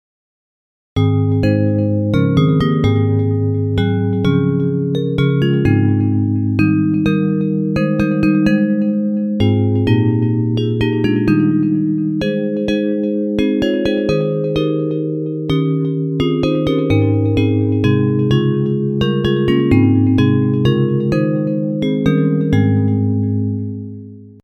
Hymns of praise
Bells Version